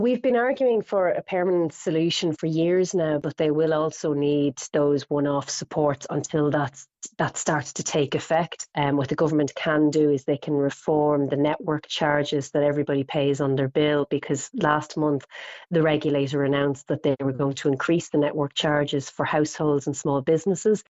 The party’s Dublin MEP, Lynn Boylan, says the Government needs to support people in the upcoming budget: